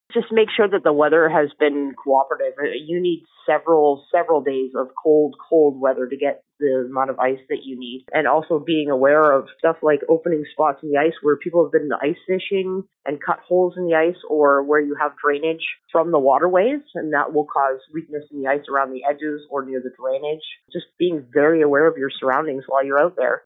In a past interview